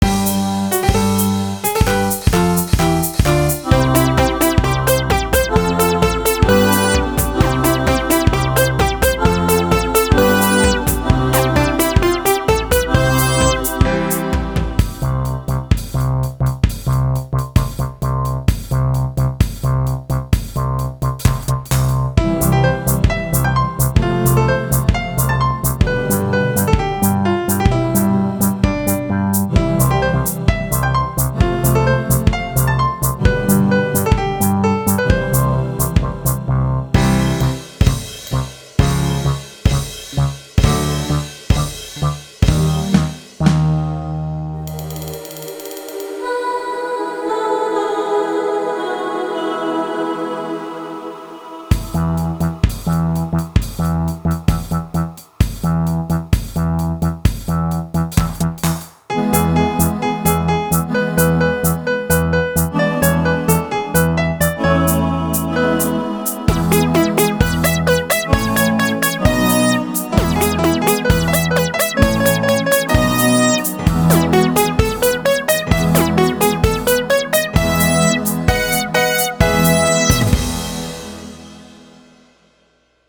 リズムに合わせて全身を動かせるので、準備運動として取り入れるのもおすすめです。